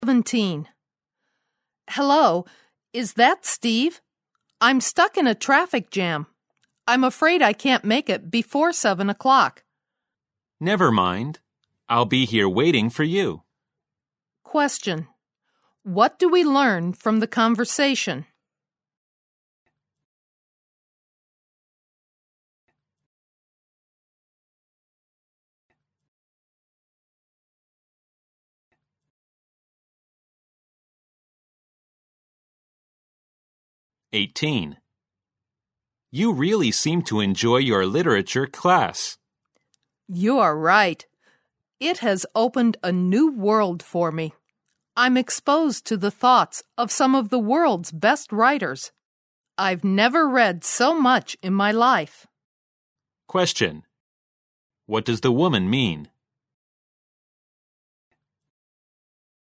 新编大学英语四级听力短对话每日2题第4期